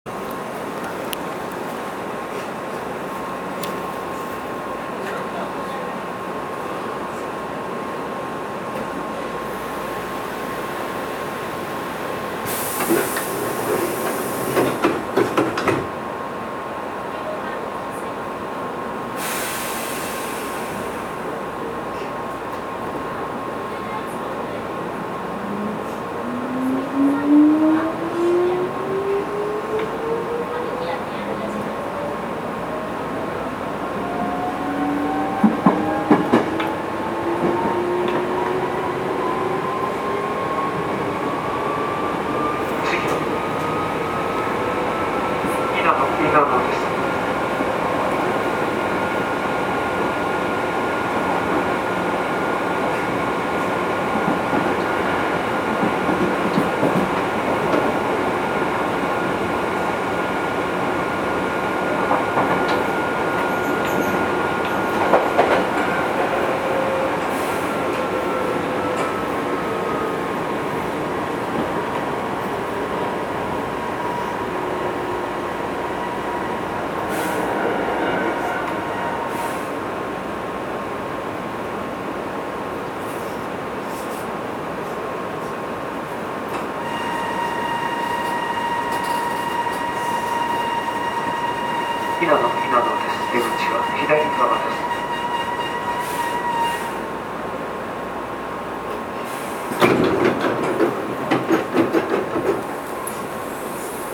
走行音
界磁チョッパ制御
録音区間：新伊丹～稲野(お持ち帰り)
Hnq7000-chopper.mp3